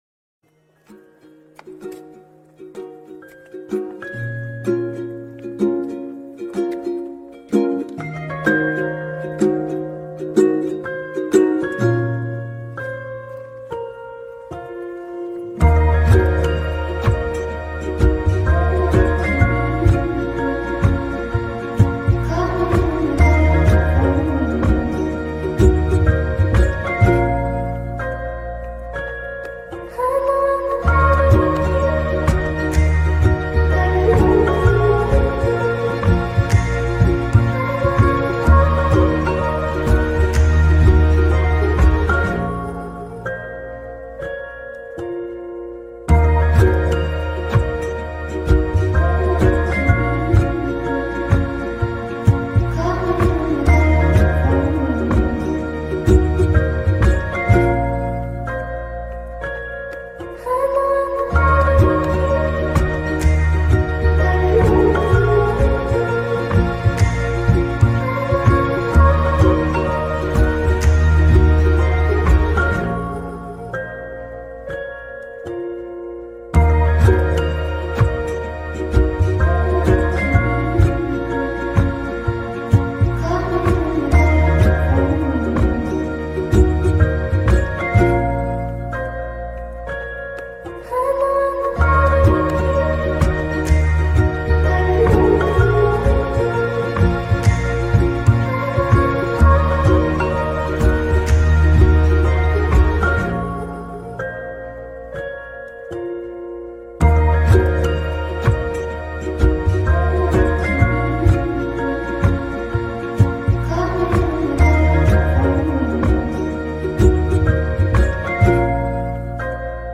سبک ریمیکس , کی پاپ , هیپ هاپ